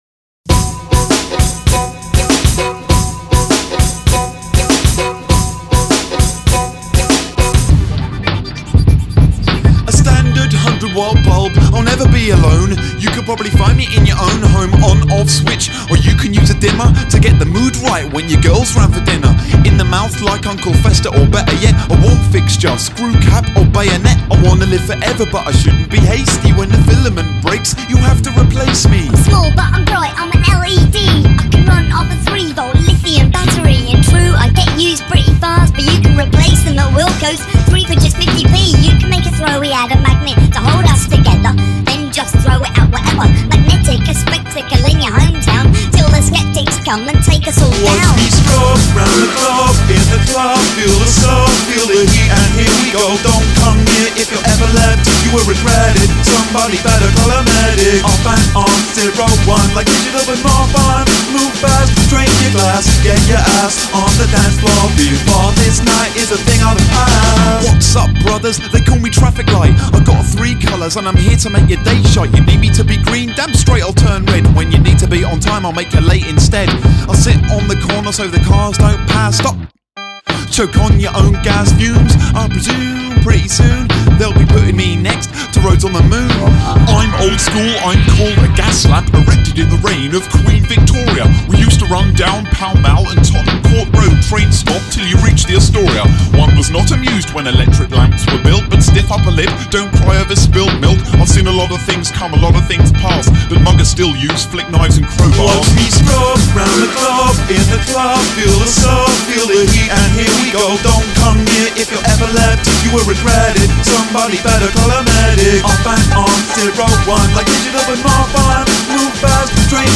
Multiple Narrators